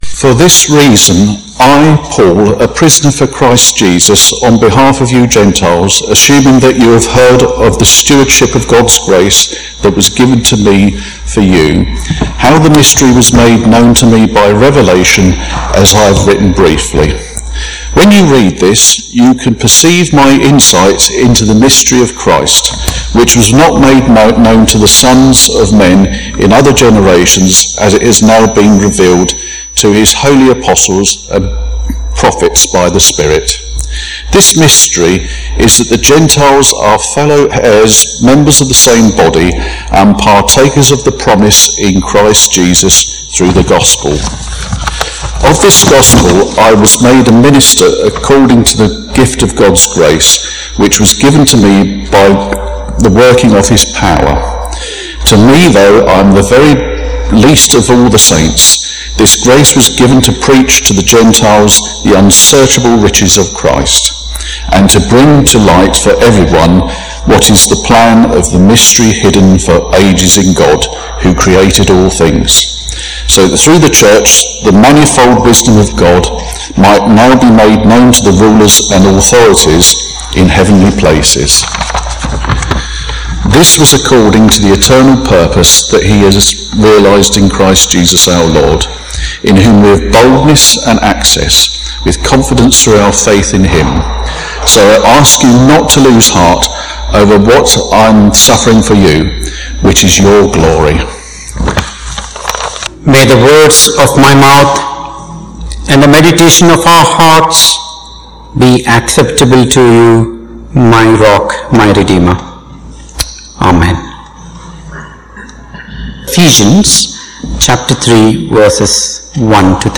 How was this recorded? Passage: Ephesians 3:1-13 Service Type: Morning Service